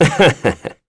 Esker-Vox_Happy2.wav